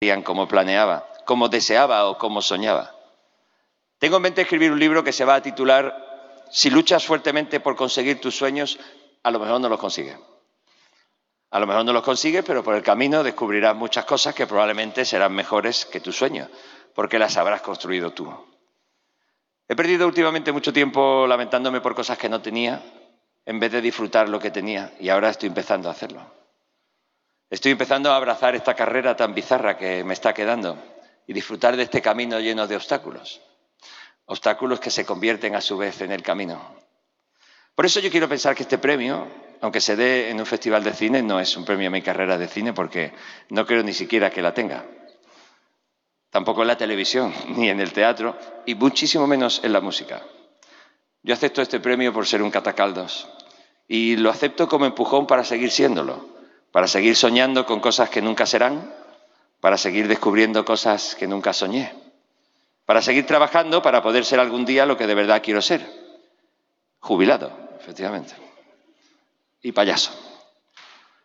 Un acto de entrega que ha tenido lugar en el Patio de Luces de la Diputación Provincial